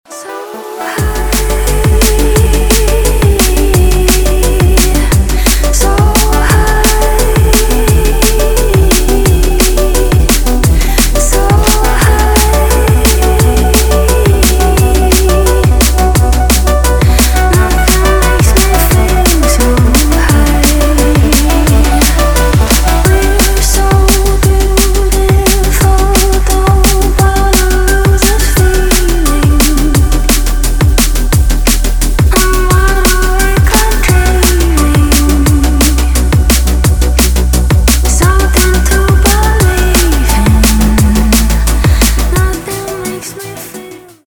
• Качество: 320, Stereo
Electronic
красивый женский голос
Liquid DnB
драм энд бейс